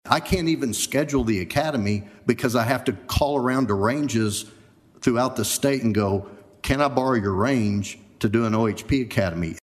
CLICK HERE to listen to commentary from Tim Tipton.